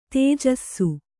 ♪ tējassu